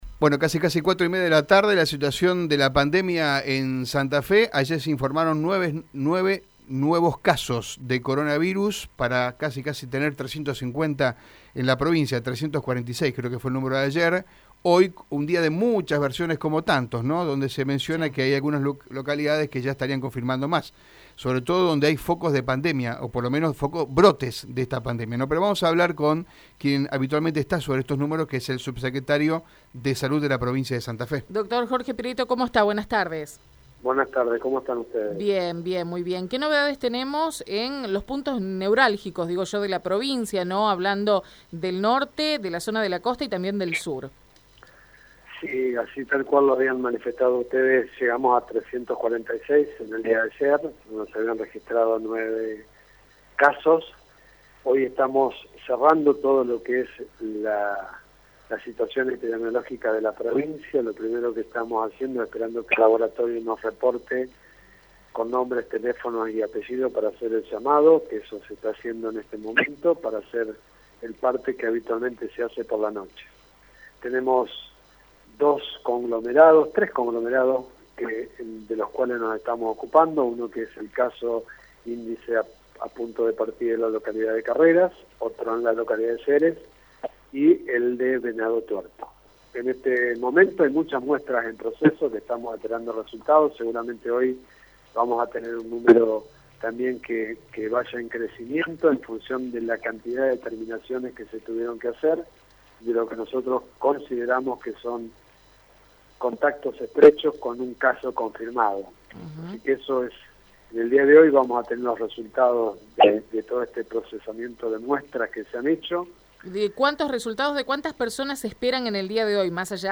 Jorge Prieto, subsecretario de Salud de la provincia de Santa Fe, habló en Radio EME sobre la situación epidemiológica por el Coronavirus y las nuevas medidas restrictivas en el territorio santafesino.